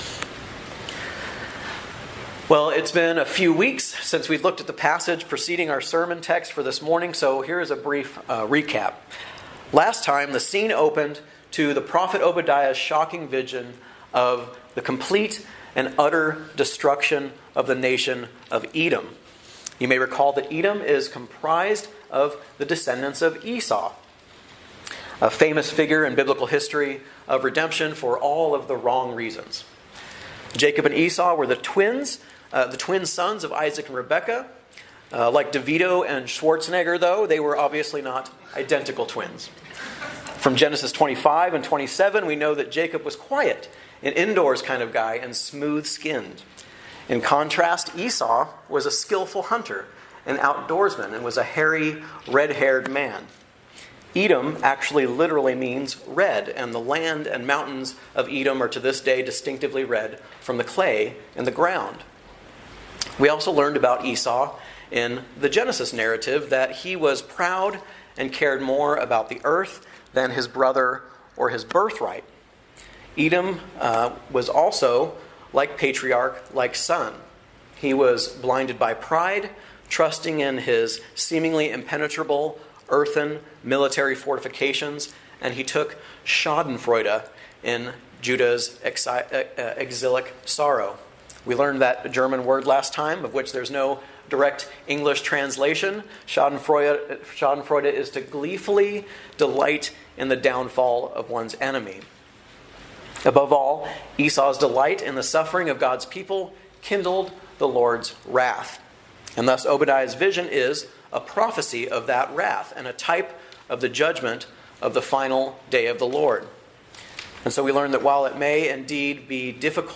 A message from the series "Guest Preacher." Obadiah 11-13